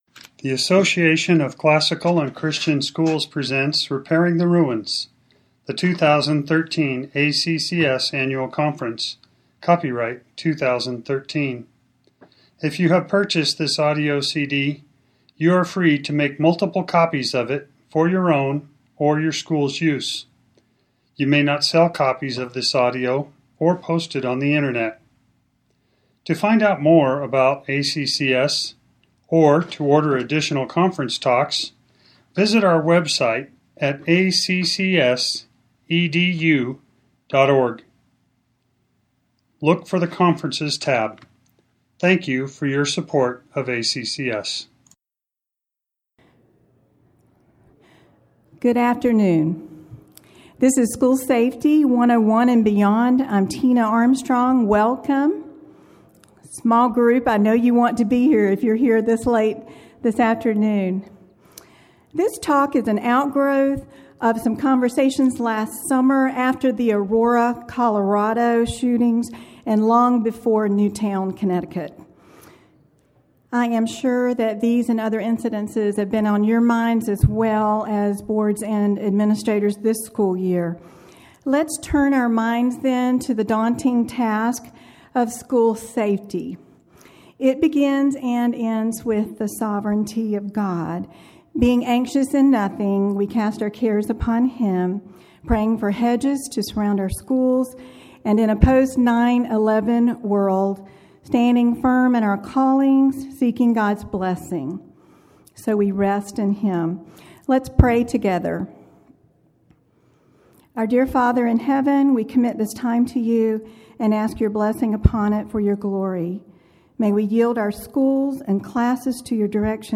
2013 Workshop Talk | 0:58:56 | All Grade Levels, Leadership & Strategic
Jan 19, 2019 | All Grade Levels, Conference Talks, Leadership & Strategic, Library, Media_Audio, Workshop Talk | 0 comments